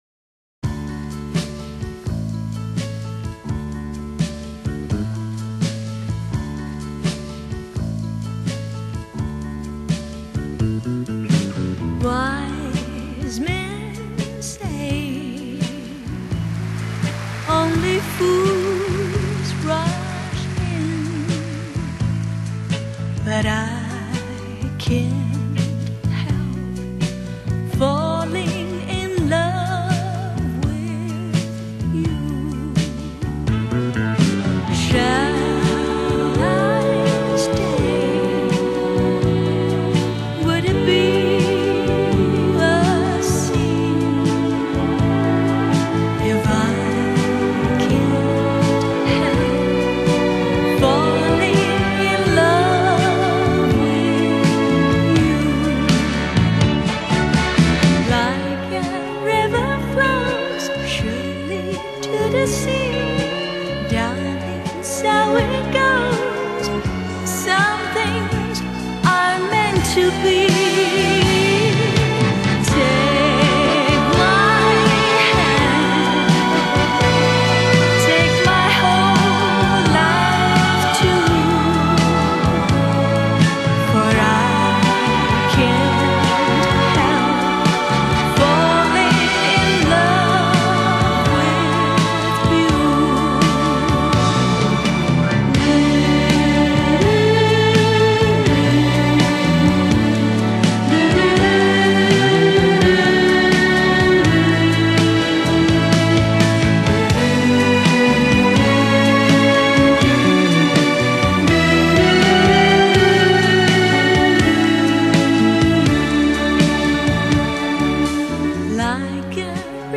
情に墮することなく、技の勝ることなく、ふくよかな歌唱を聴かせる。